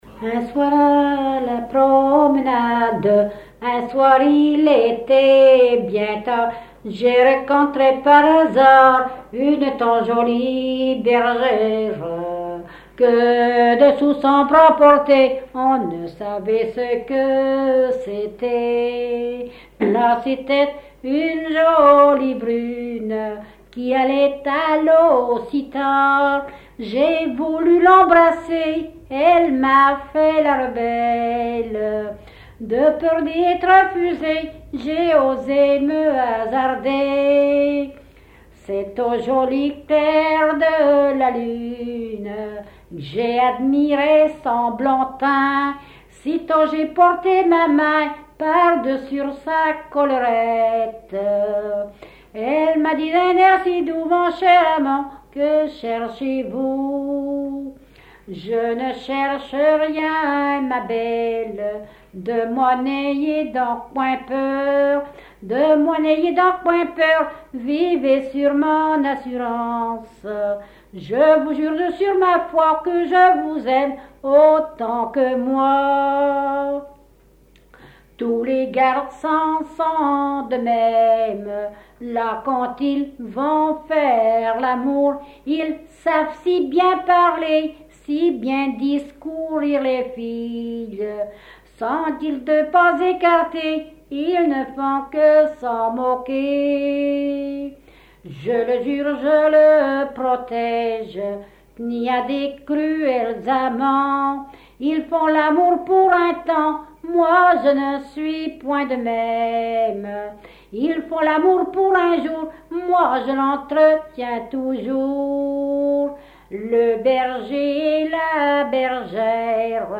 Localisation Barbâtre (Plus d'informations sur Wikipedia)
Genre strophique
Catégorie Pièce musicale inédite